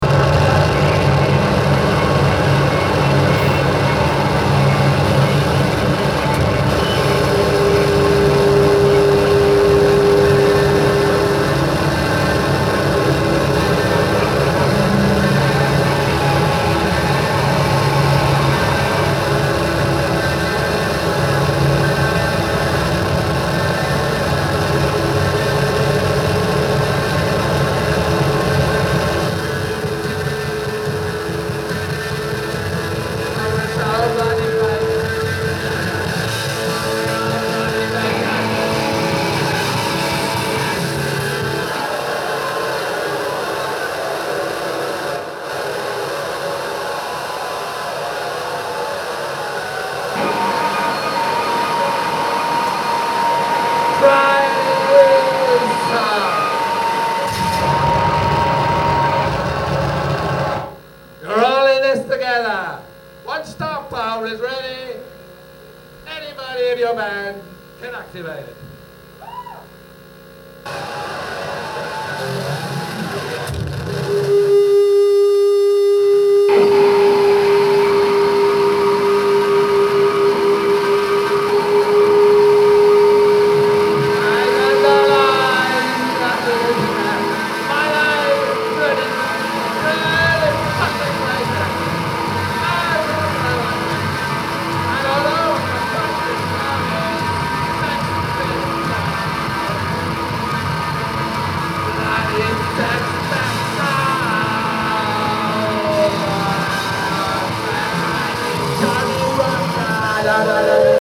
plunderphonics, noise, improv and techno